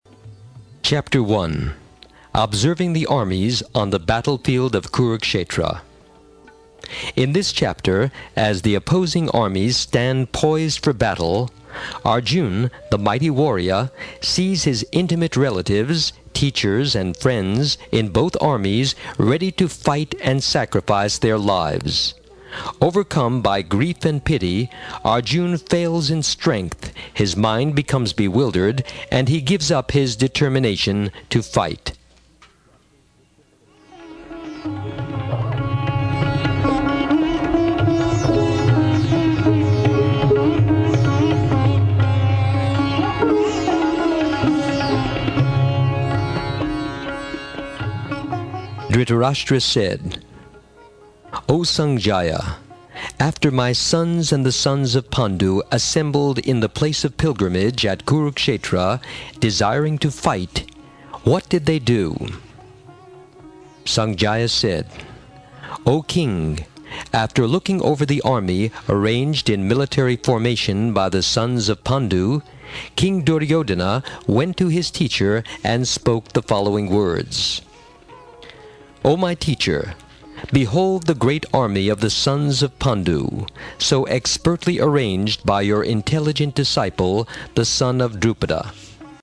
A Narration of the Book
Die Erzählung des Buches Bhagavad-gita wie sie ist von Srila Prabhupada (nur die Verse), mit indischer Hintergrundmusik.
Erzählung (engl.) im MP3-Format, mit indischer Hintergrundmusik, Inhaltsverzeichnis auf der CD vorhanden.
Hinweis: während der Übertragung von den Original-Kassetten in's MP3-Format sind kleine Qualitätseinbussen entstanden.